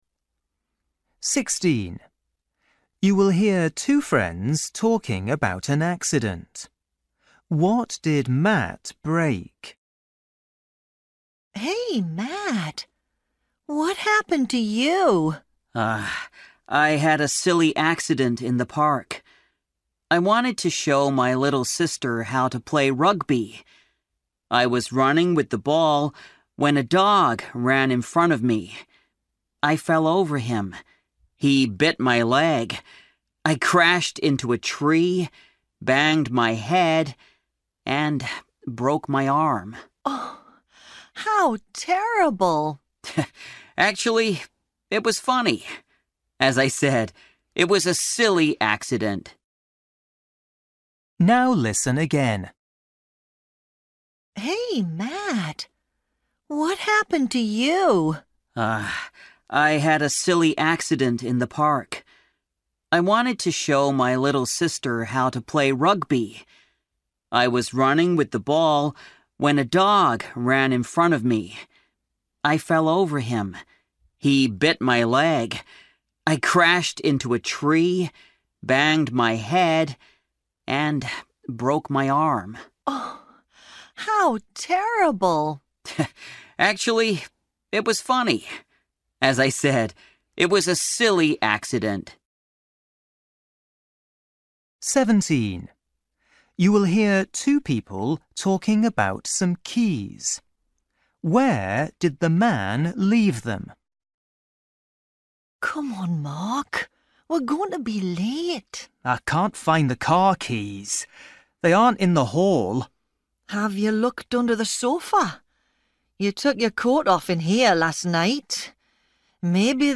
Listening: everyday short conversations
16   You will hear two friends talking about an accident. What did Matt break?
17   You will hear two people talking about some keys. Where did the man leave them?
18   You will hear two friends talking in a restaurant. What does the woman decide to eat?